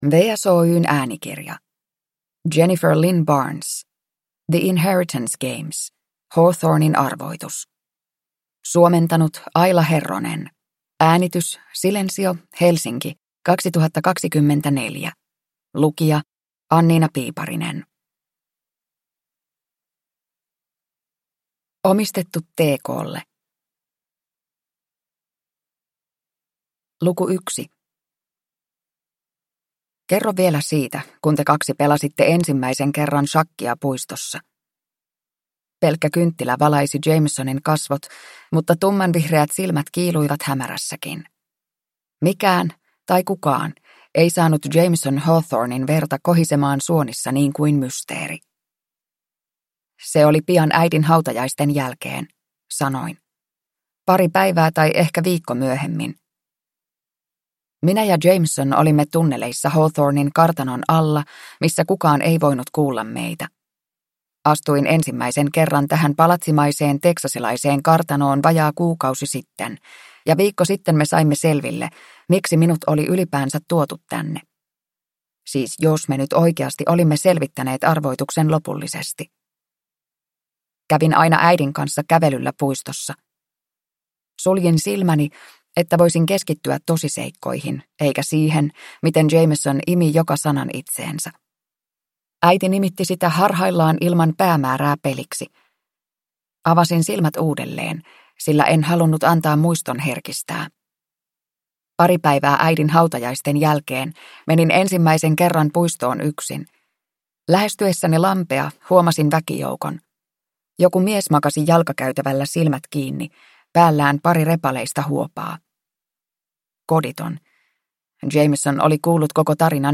The Inheritance Games: Hawthornen arvoitus (ljudbok) av Jennifer Lynn Barnes